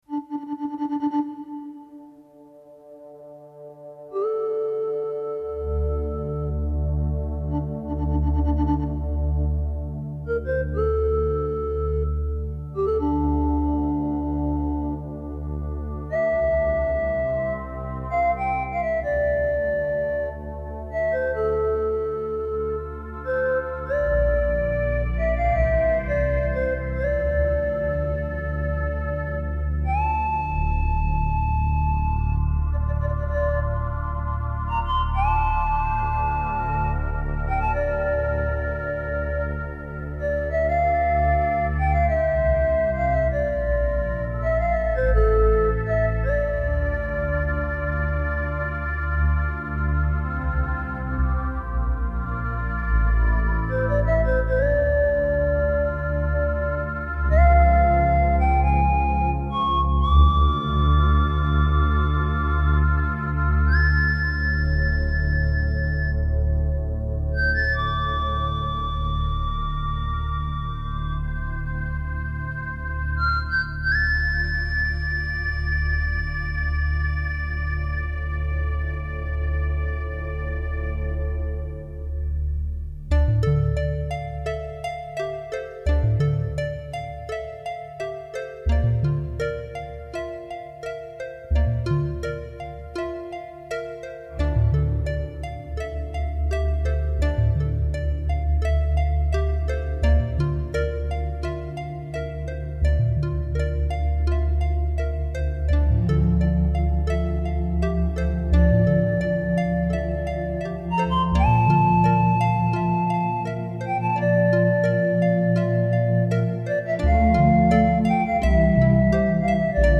All sounds must be produced by one or more ESQ1 and/or SQ80.